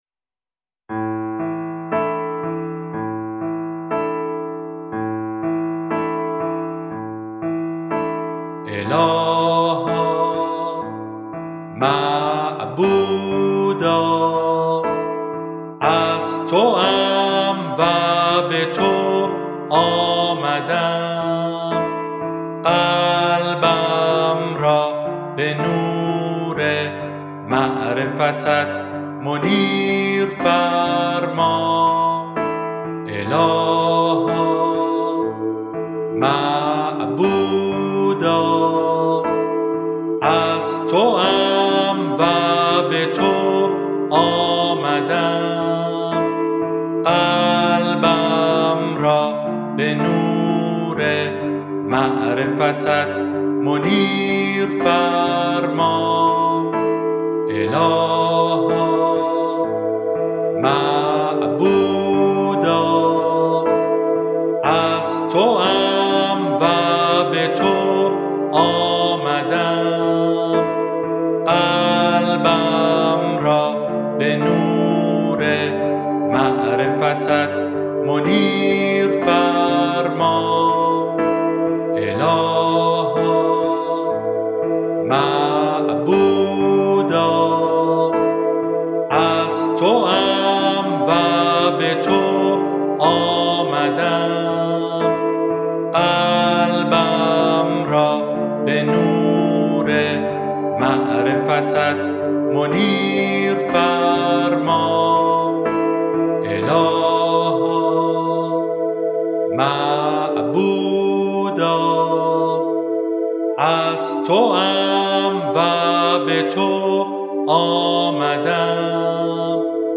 ذکر - شماره 8 | تعالیم و عقاید آئین بهائی
Download Track8.mp3 سایر دسته بندیها اذکار فارسی (آوازهای خوش جانان) 12027 reads Add new comment Your name Subject دیدگاه * More information about text formats What code is in the image?